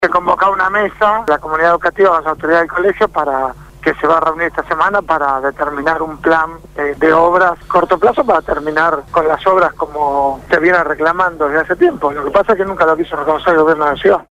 Quién participó del abrazo al Normal 7- habló esta mañana con el programa «Punto de Partida» (Lunes a viernes de 7 a 9 de la mañana) por Radio Gráfica FM 89.3